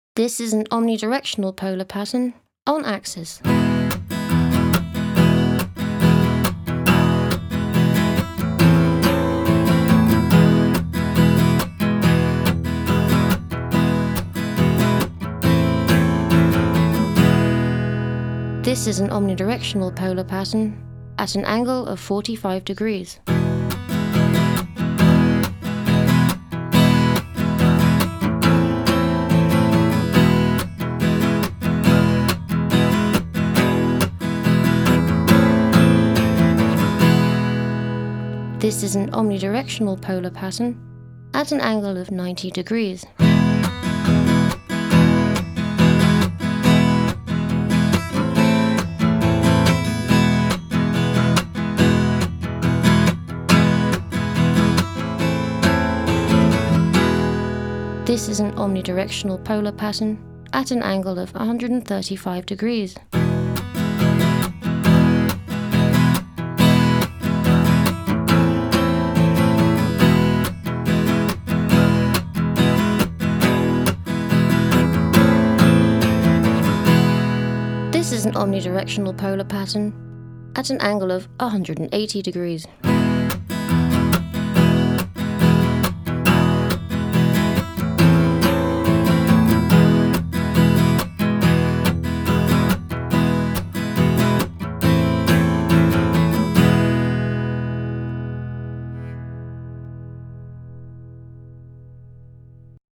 Omnidirectionnel:
Les micros omnidirectionnels (directivité 1 sur l’image) captent le son provenant de toutes les directions.
En lisant le fichier audio 1, vous entendrez une guitare acoustique enregistrée par le biais d’un micro omnidirectionnel.
1.-Omnidirectional.mp3